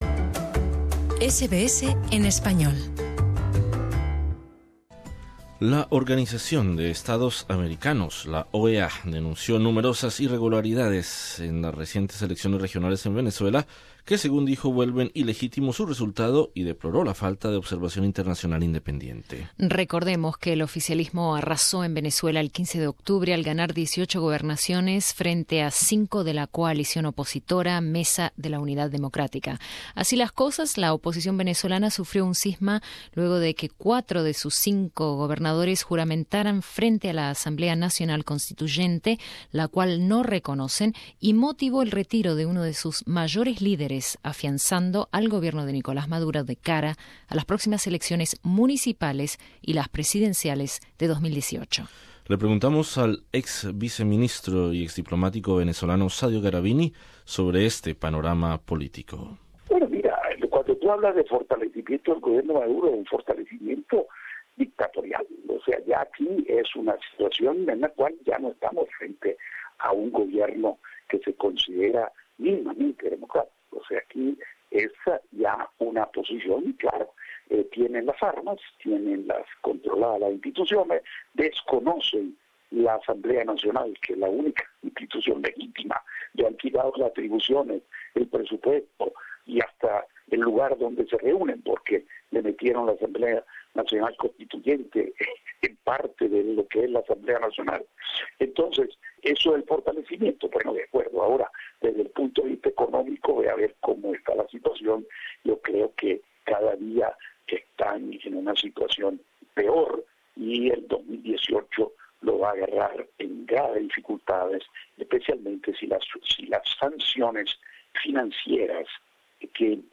Entrevistados